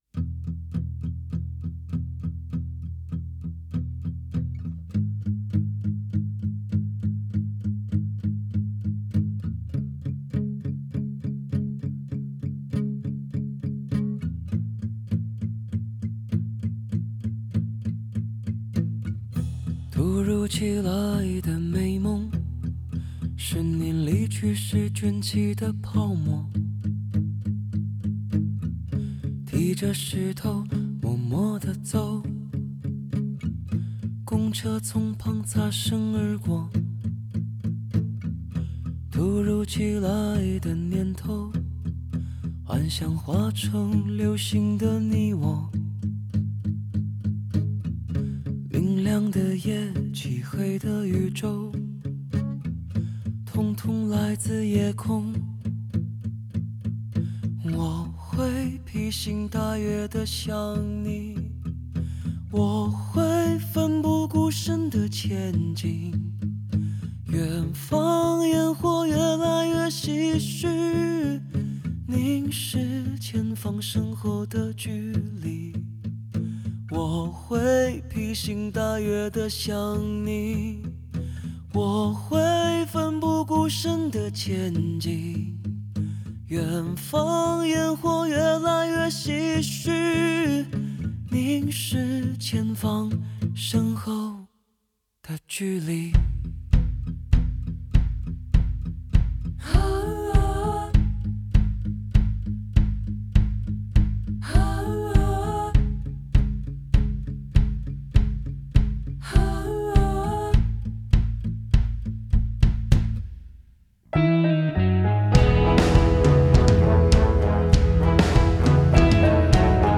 Ps：在线试听为压缩音质节选，体验无损音质请下载完整版 MP3